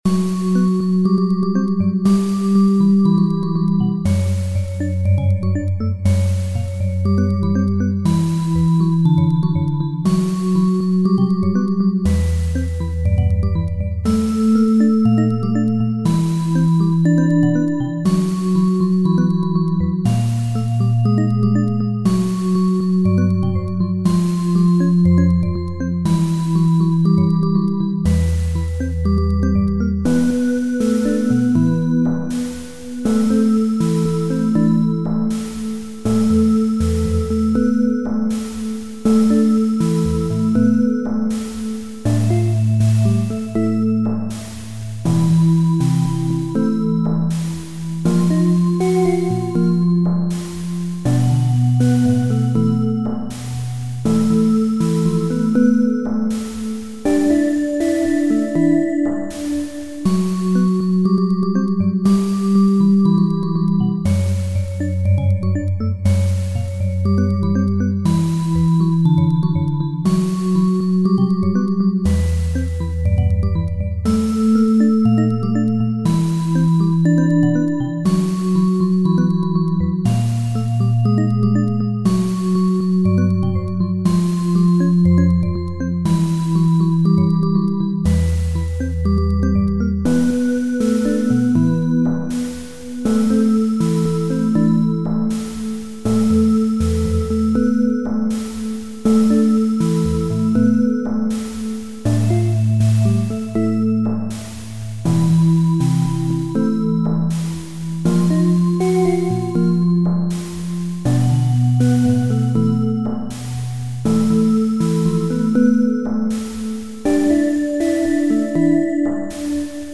Presently, it creates interesting "repetitive but varying" riffs, each about 30 seconds long, consisting of a rhythmic "measure" (called a "repUnit") that repeats with allowable variations perhaps 4 to 6 times in the riff.
I took 3 of the riffs I happened to like (herein, called A, B and C) and simply repeated them in the sequence ABABCC, hence the name of this "song".